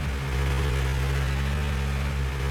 boatengine_revlowloop.wav